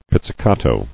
[piz·zi·ca·to]